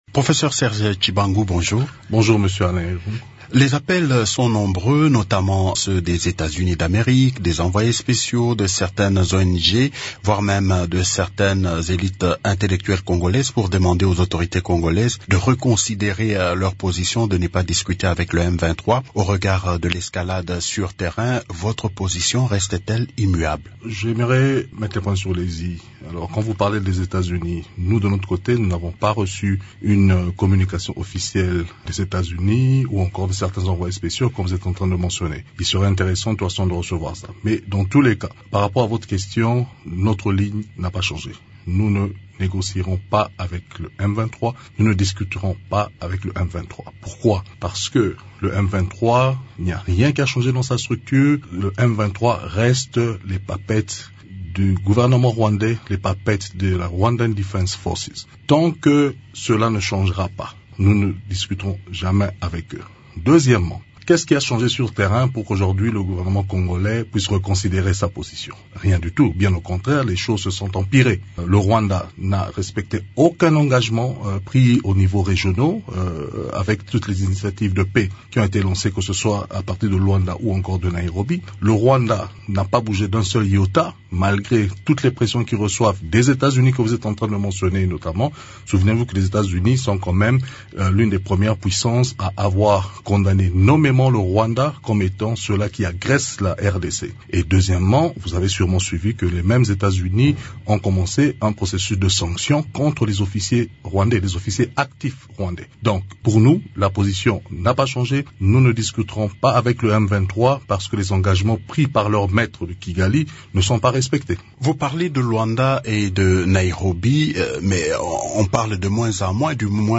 integralite_interview_prof_serge_tshibangu_web.mp3